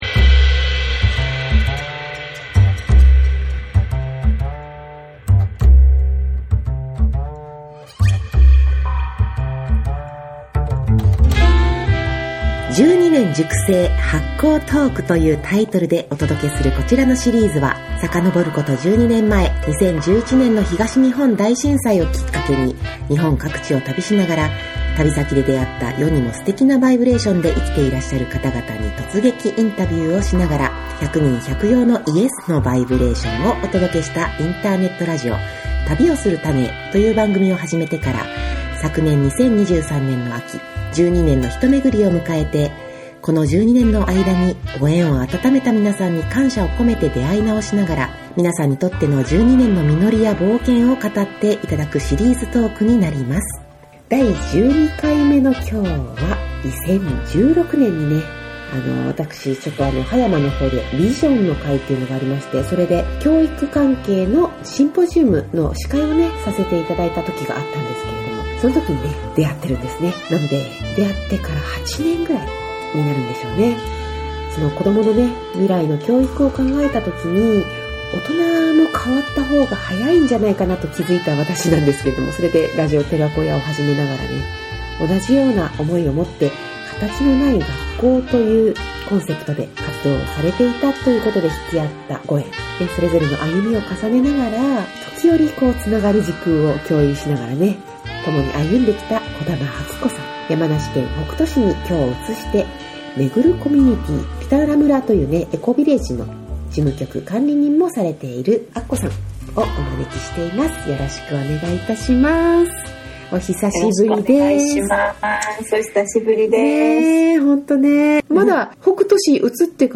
12年熟成＆発酵トーク✨